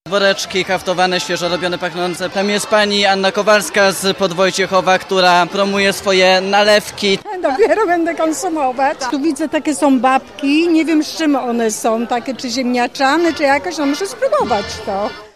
mowia_goscie.mp3